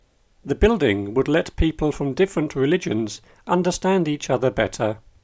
DICTATION 9